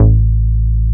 R MOOG A2MP.wav